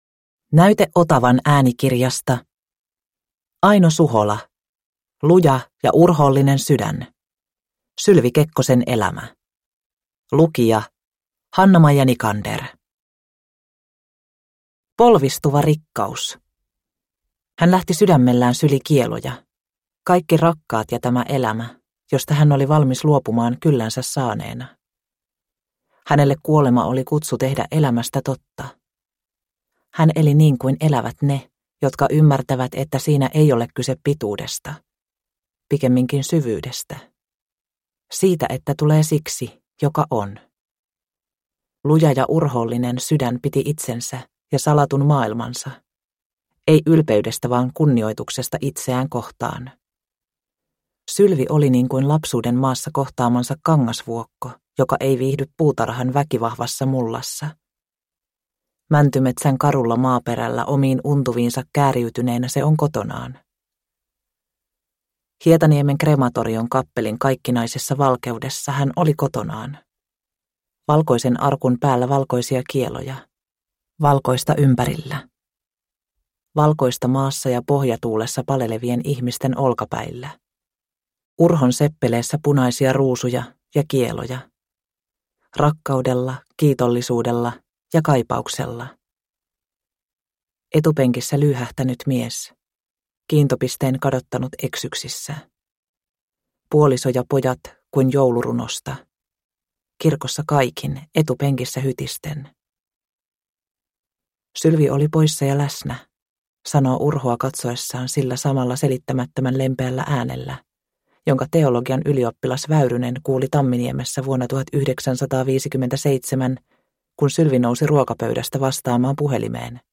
Luja ja urhoollinen sydän – Ljudbok – Laddas ner